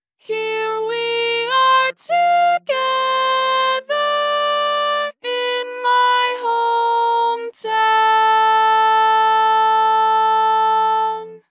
Key written in: F Major
Type: Female Barbershop (incl. SAI, HI, etc)